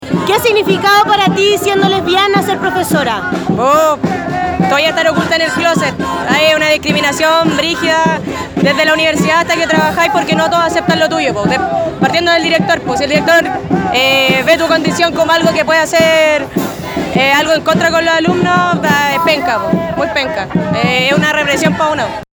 Desde la Izquierda Diario conversamos con varios docentes a lo largo de la marcha sobre lo que viven día a día en las salas de clase, siendo parte de la diversidad sexual.